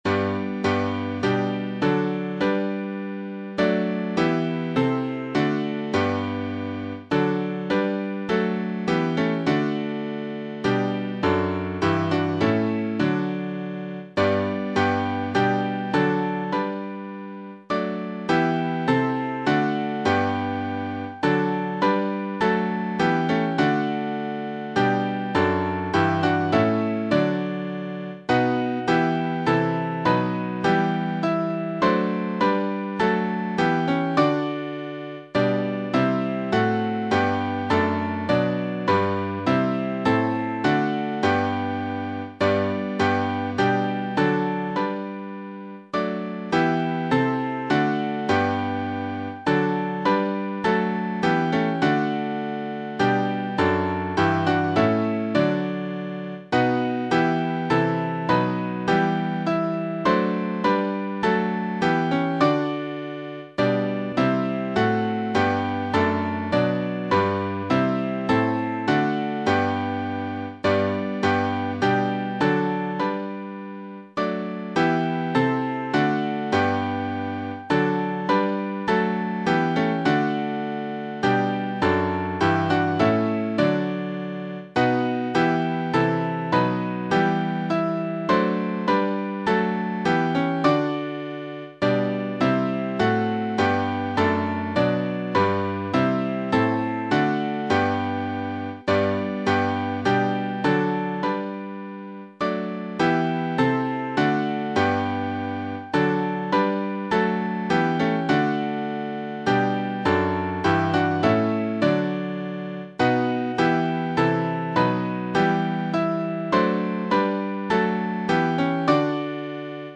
Midi File Transcriptions